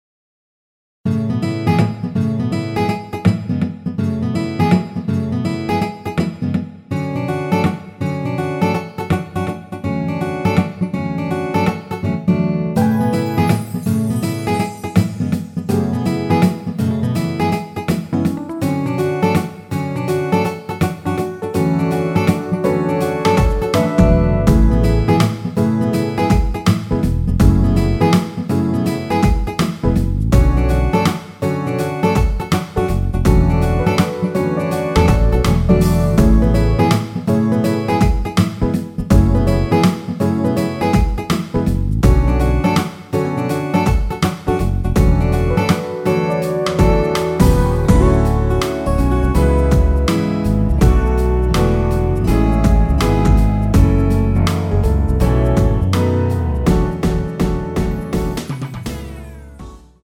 (-2)내린 MR
앞부분30초, 뒷부분30초씩 편집해서 올려 드리고 있습니다.
중간에 음이 끈어지고 다시 나오는 이유는